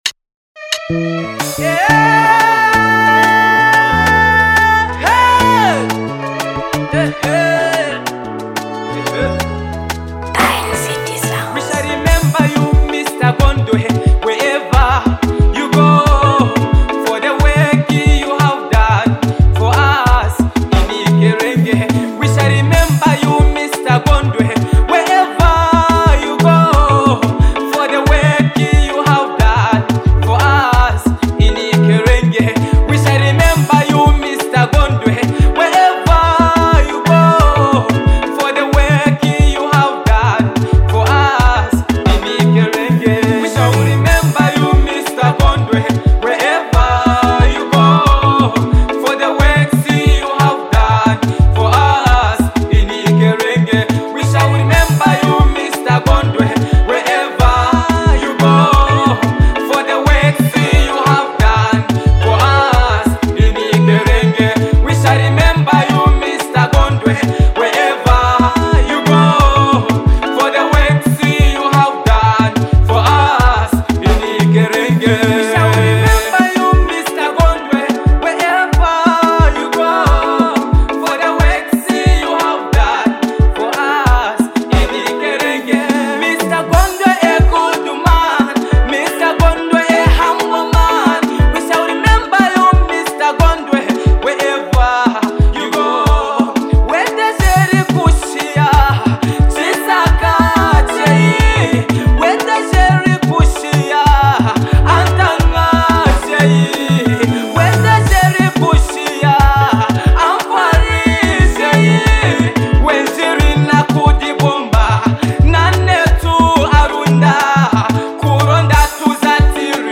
A Song of Appreciation & Legacy
With soulful melodies, powerful lyrics, and heartfelt praise